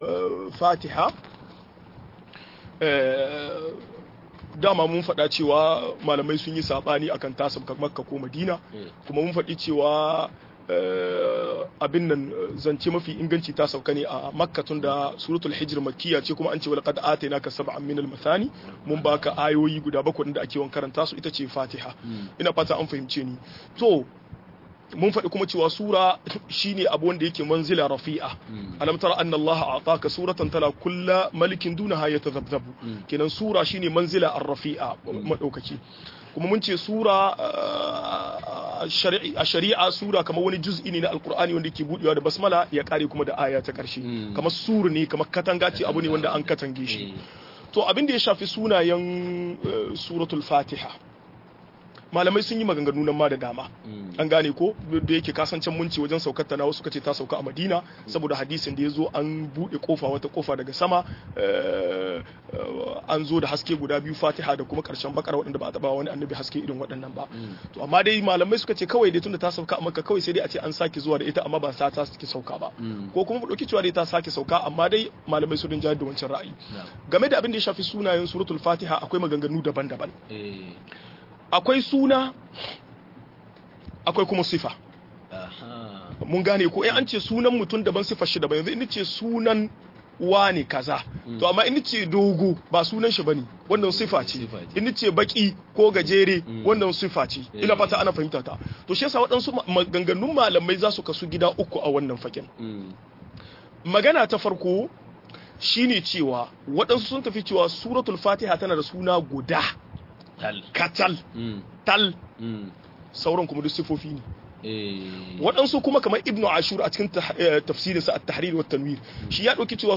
Fatiha-01 - MUHADARA